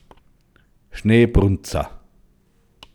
Männer, die im Winter in den Schnee pinkeln Reith im Alpbachtal